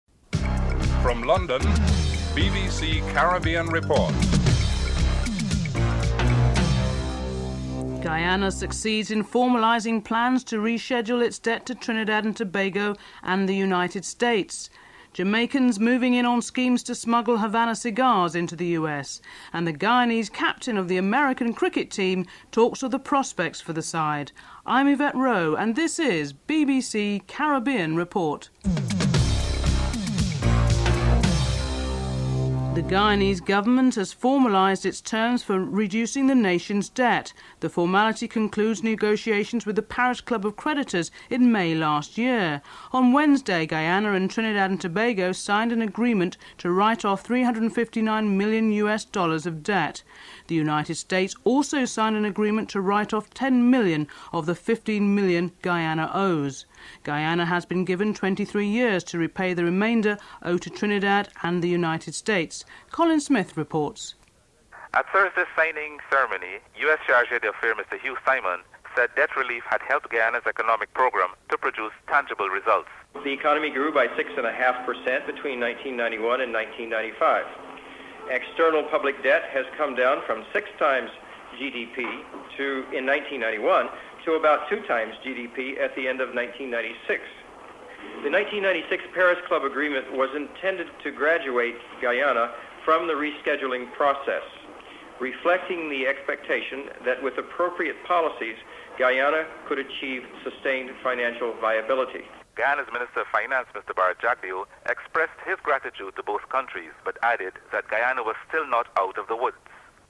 1. Headlines (00:00-00:31)
5. Life in Montserrat almost two years after the Soufriere Hill volcano comes alive. Montserratians are interviewed (06:59-10:43)